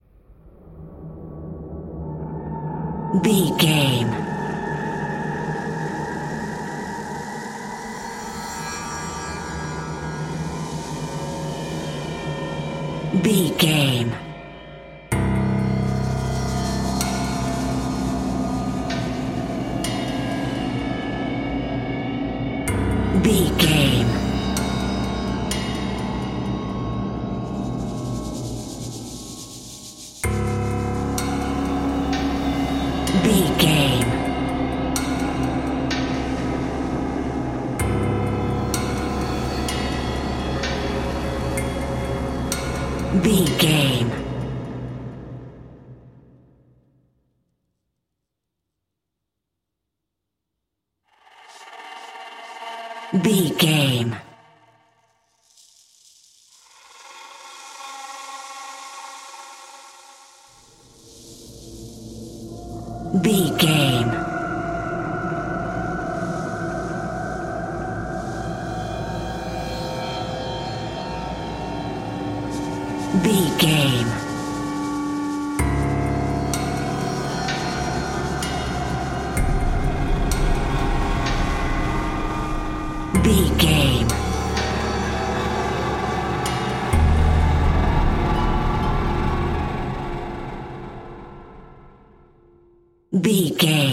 Atonal
drone
medium tempo
drum machine